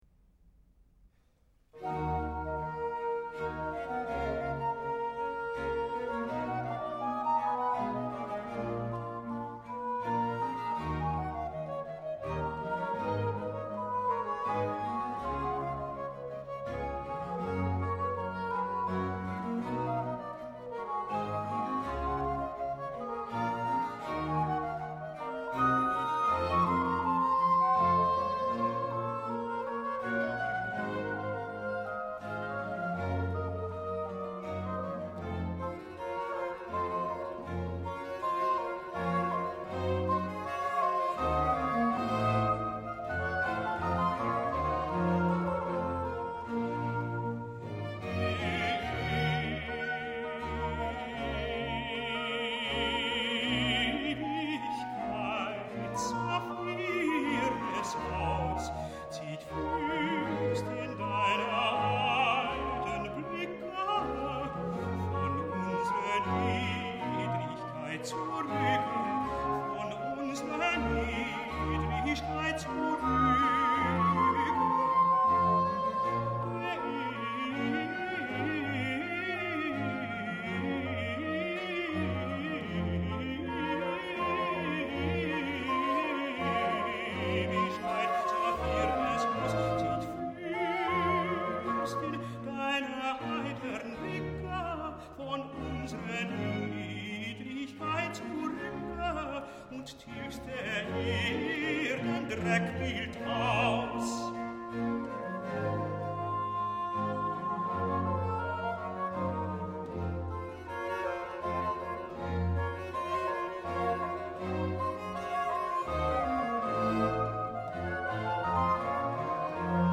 Eδώ που κάθε γραμμή έχει διαφορετικό υλικό και παίζεται από διαφορετικό όργανο είναι πιο εύκολο να τις ξεχωρίζουμε – ή τουλάχιστον να ξεχωρίζουμε περιστασιακά θραύσματα από δω κι από κει. Oύτως ή άλλως προκύπτει πλούτος και μιά ωραία καλειδοσκοπική αίσθηση.